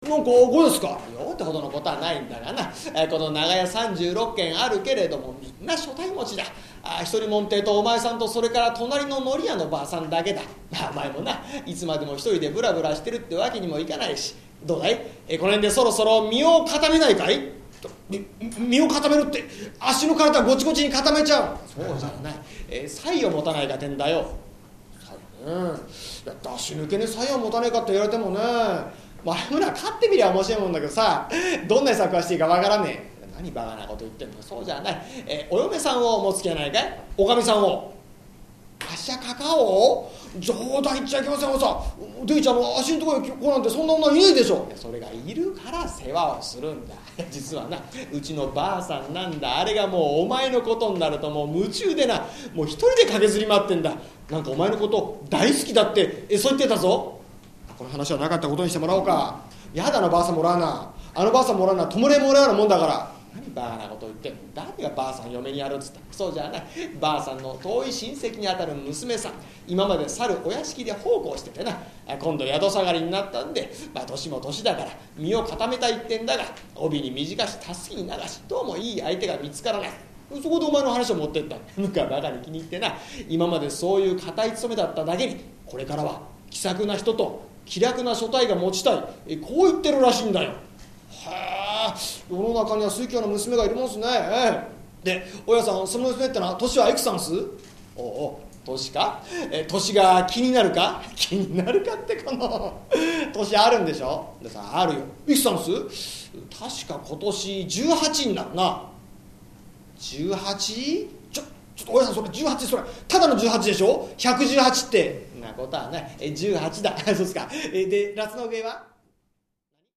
こども落語